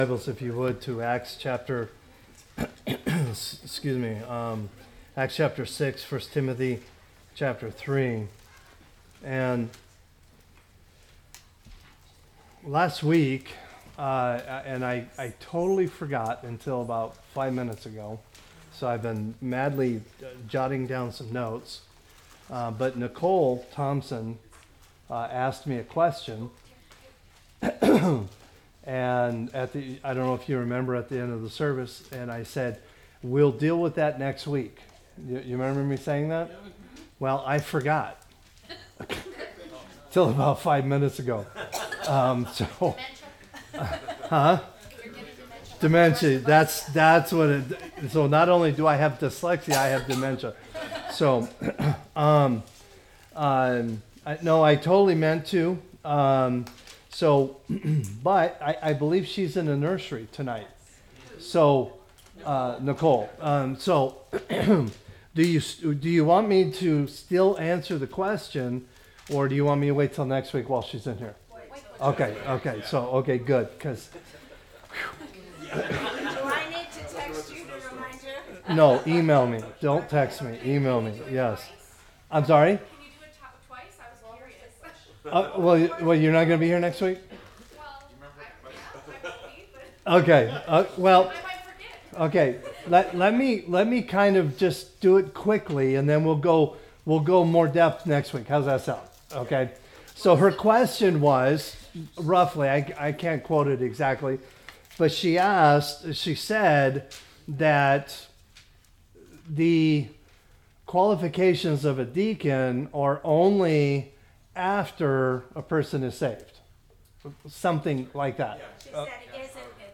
Sermons | Grace Baptist Church